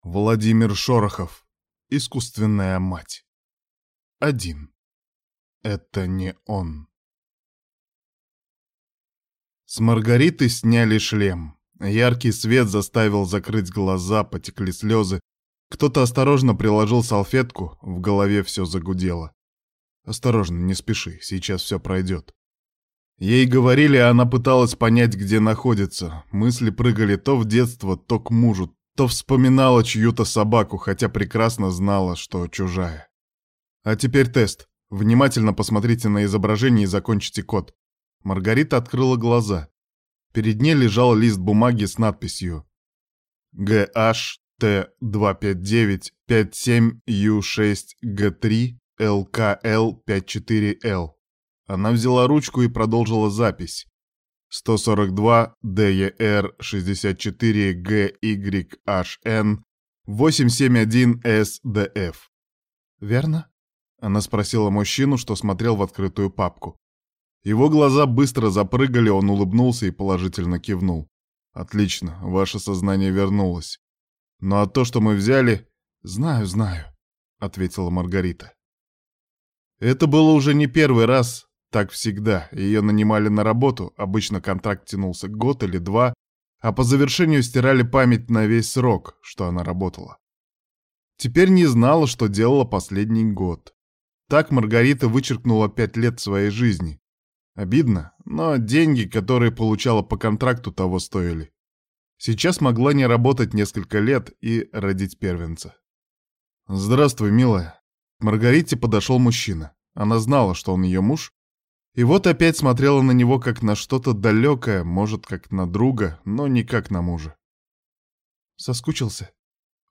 Аудиокнига Искусственная мать | Библиотека аудиокниг